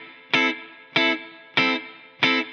DD_TeleChop_95-Amin.wav